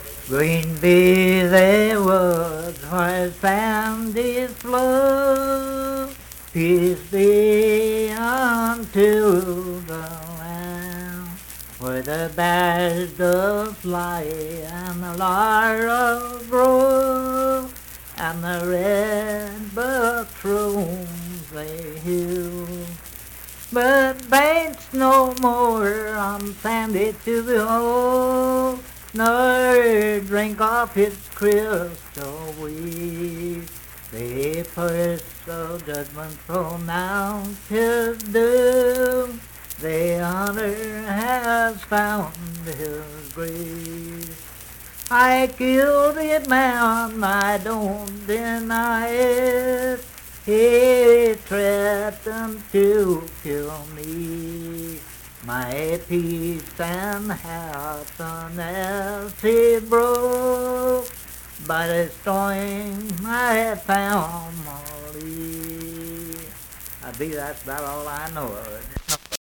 Unaccompanied vocal music
Verse-refrain 2(4-8).
Voice (sung)
Huntington (W. Va.), Cabell County (W. Va.)